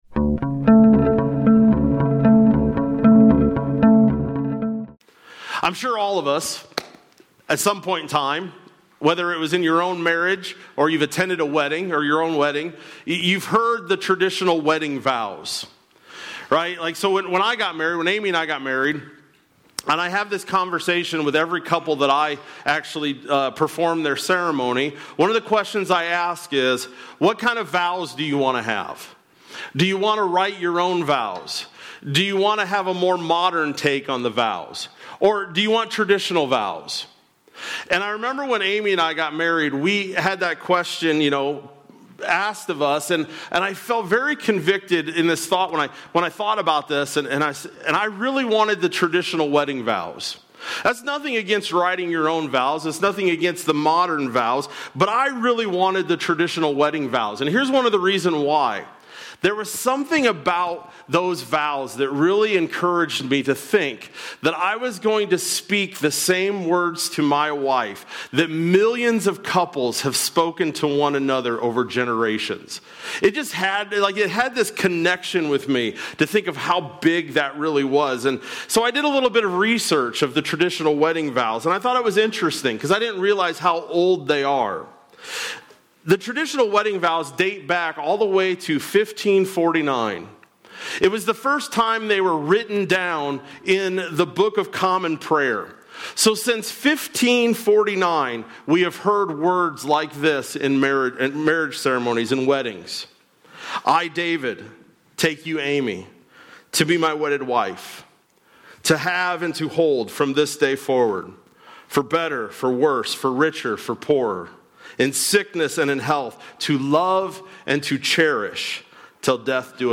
Sept-7-25-Sermon-Audio.mp3